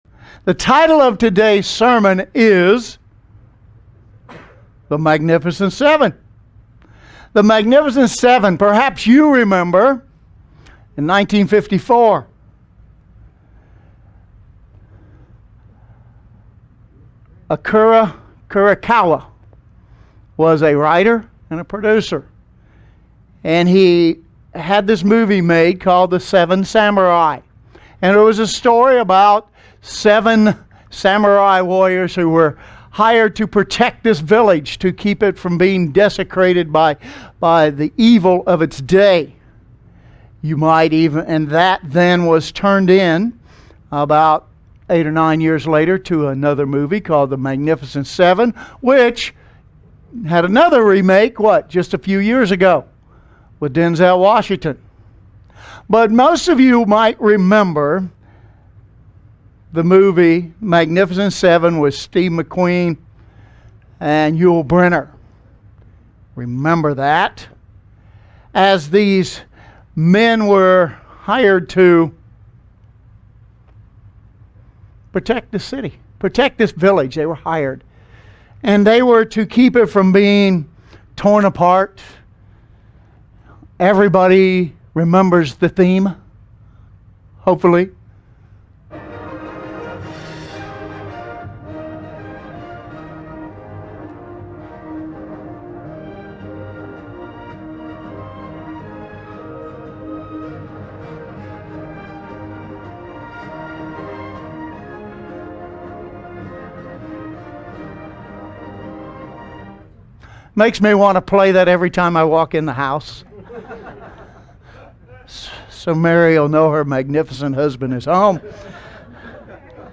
Magnificent Seven | United Church of God